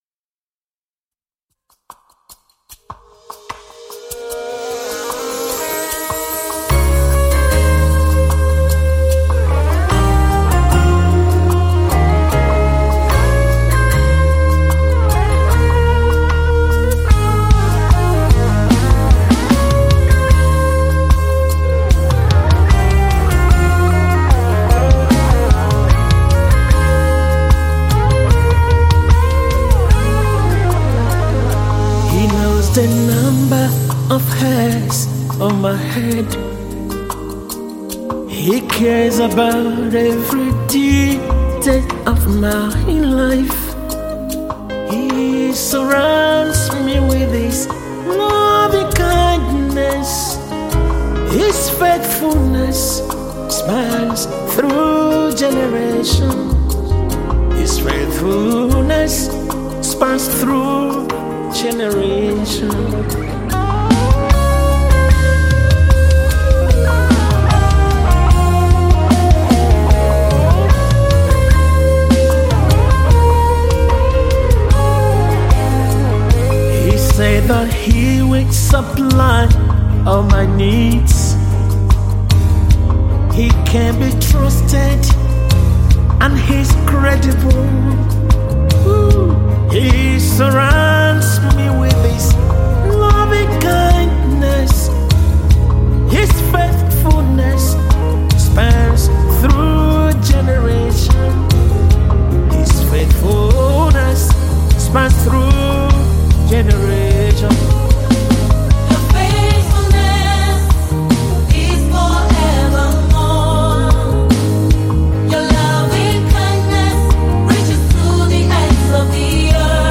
contemporary worship song
blends modern soundscapes with timeless gospel themes